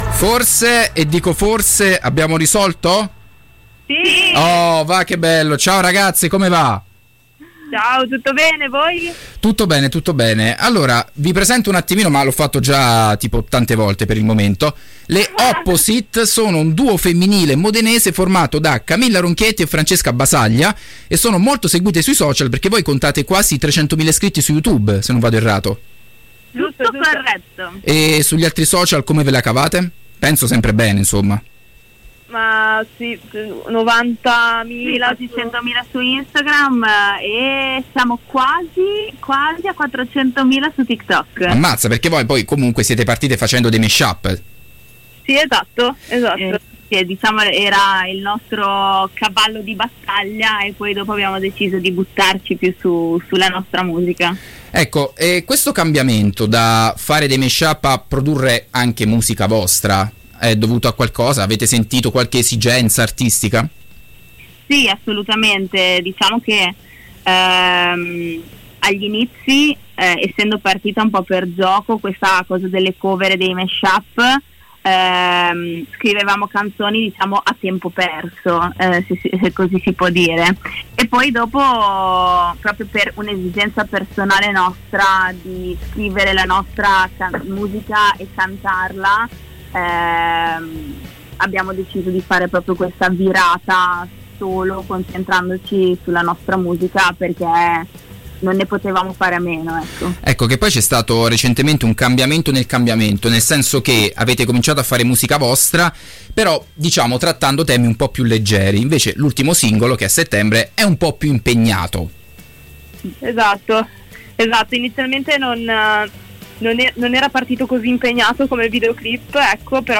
Intervista-Opposite.mp3